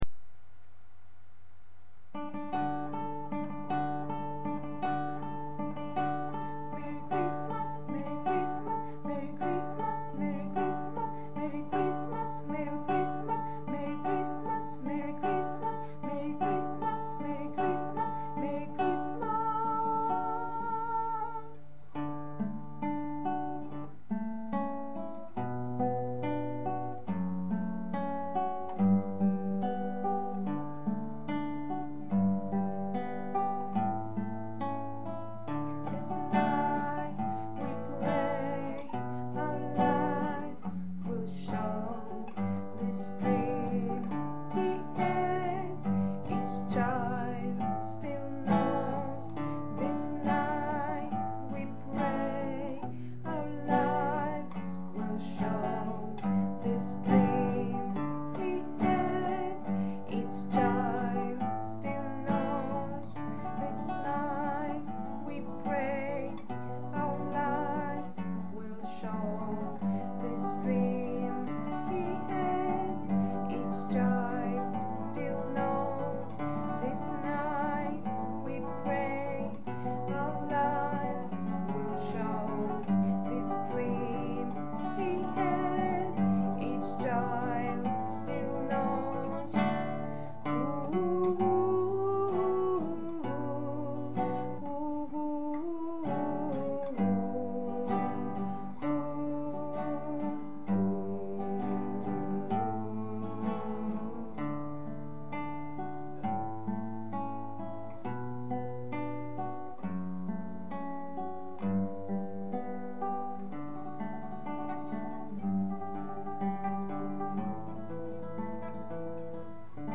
MP3 ΓΙΑ ΕΞΑΣΚΗΣΗ ΤΩΝ ΧΟΡΩΔΩΝ
CHRISTMAS CANON 2η ΦΩΝΗ